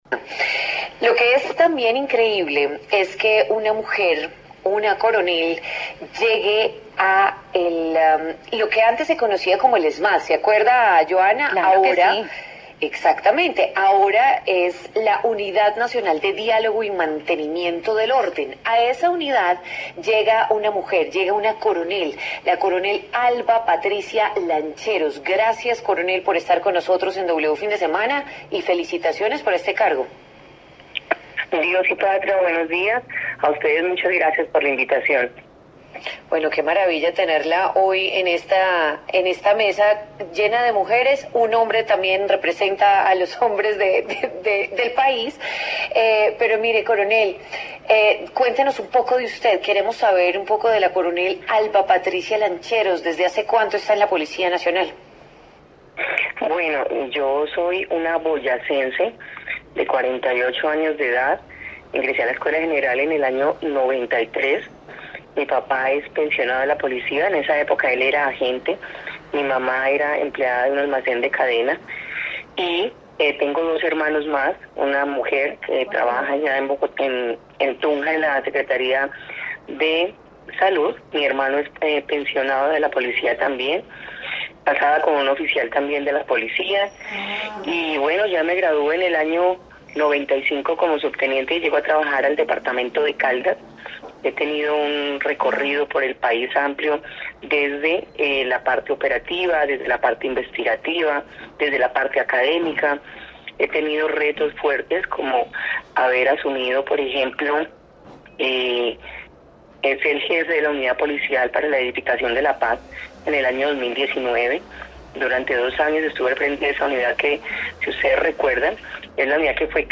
La coronel Alba Patricia Lancheros, quien fue designada como comandante de la Unidad Nacional de Diálogo y Mantenimiento del Orden, UNDMO, habló en W Fin de Semana sobre la estrategia para manejar eventuales protestas.